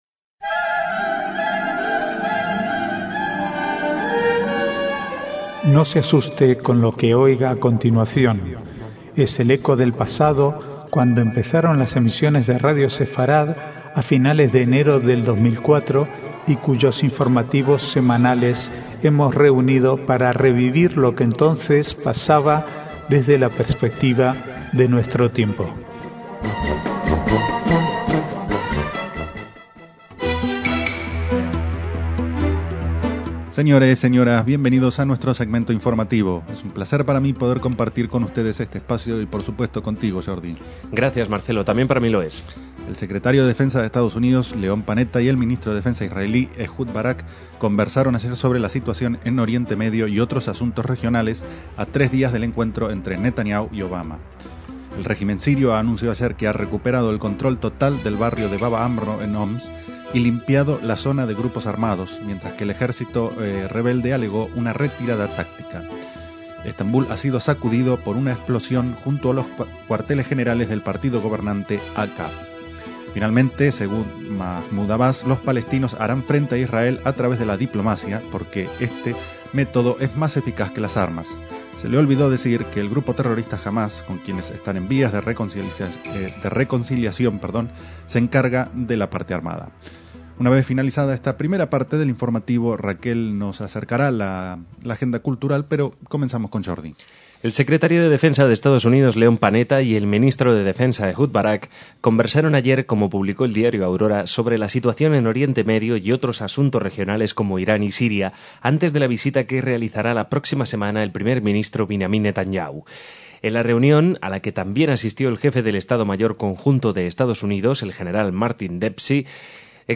Archivo de noticias del 2 al 8/3/2012